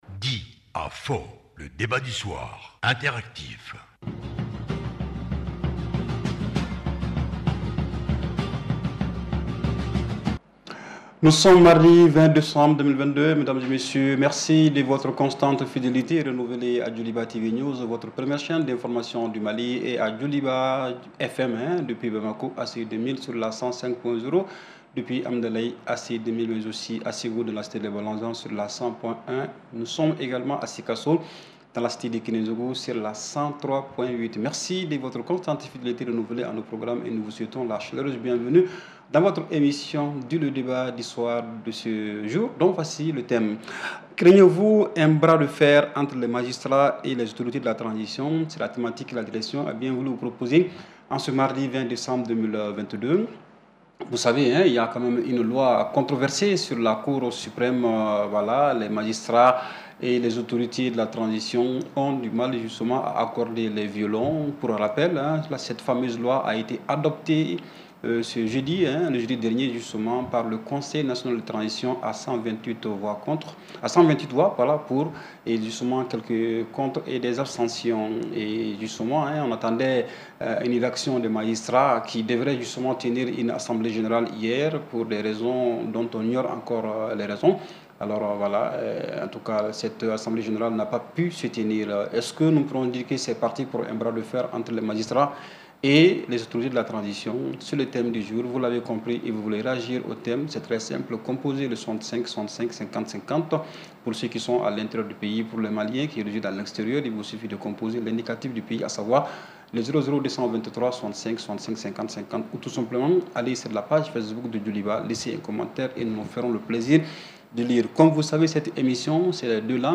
Replay 20/12/2022 - "Dis", le débat interactif du soir